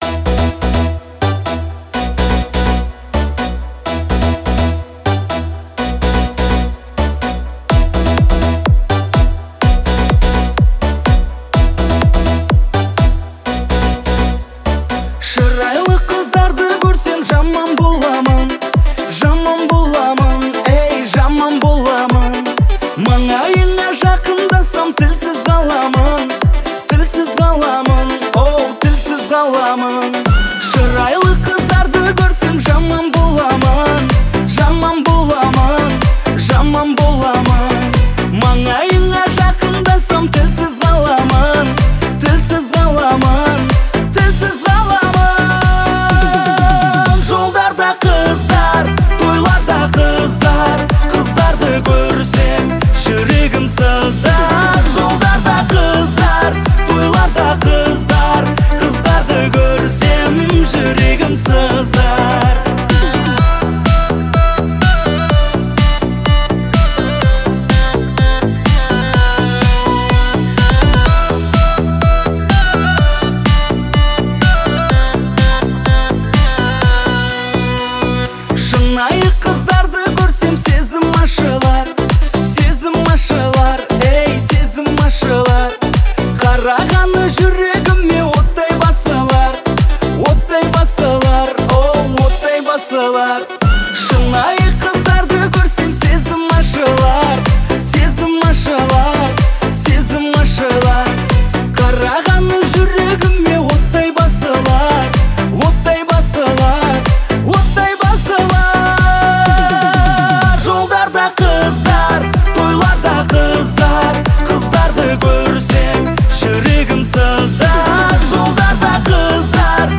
это яркий пример казахского поп-фольклора